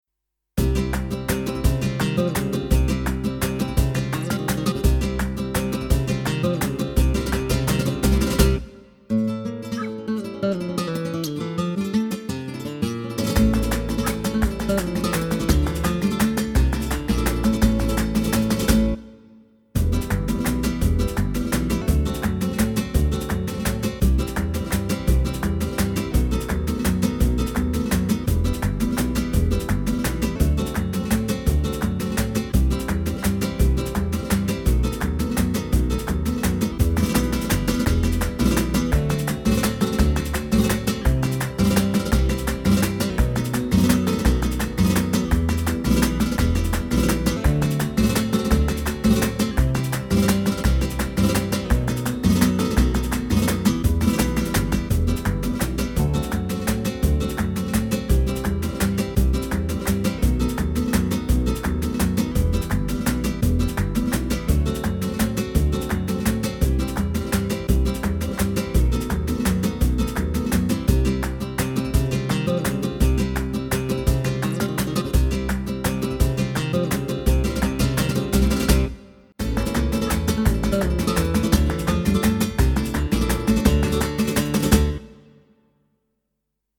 Home > Music > Blues > Bright > Folk > Running